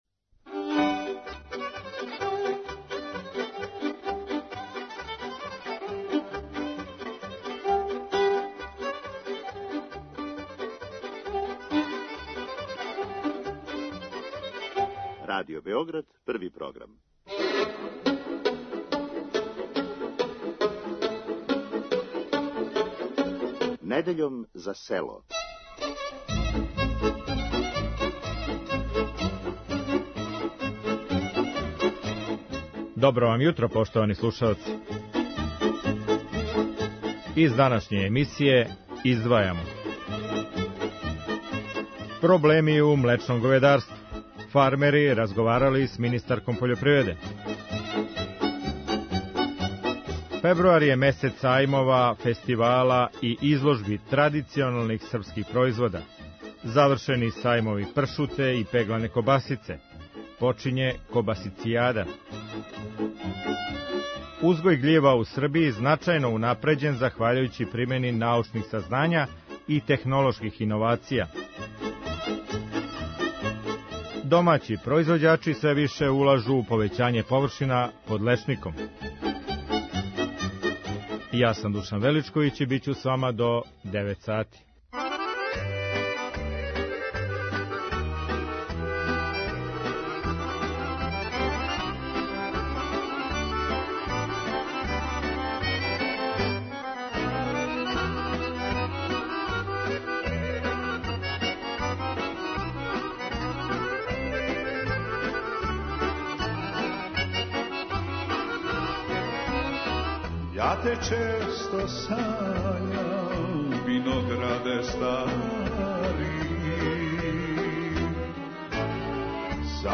О проблемима у млечном говедарству, са произвођачима разговарала министарка пољопривреде Јелена Танасковић. Најављеним повећањем премије за млеко и субвенцијама по грлу, сточари нису задовољни и од државе траже да оне буду више. Министарка Танасковић обећала је да ће до марта бити исплаћене све заостале субвенције из прошле године.